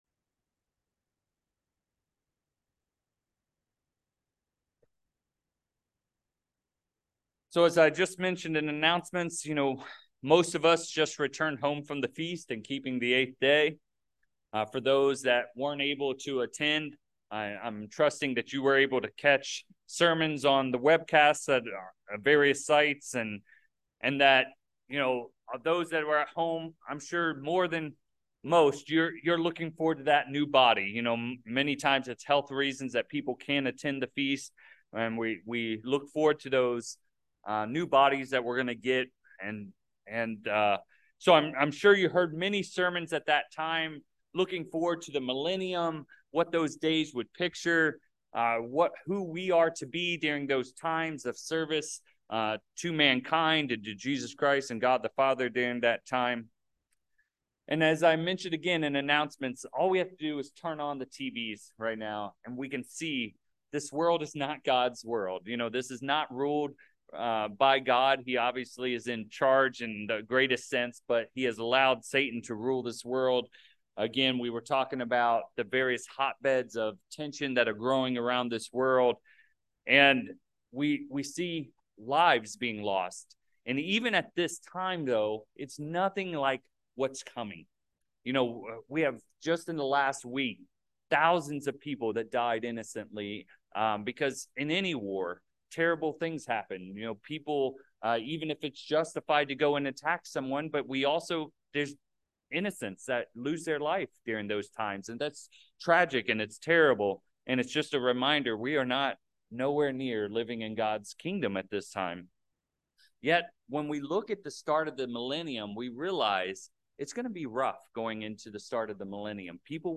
Orinda In this sermon, we dive into the depth of a Greek word (and two of its variations) that are very difficult to translate from Greek into other languages. It’s important as Christians that we understand its true meaning and how we can practice this important skill both now and in the future as we serve alongside Jesus Christ in the Millennium.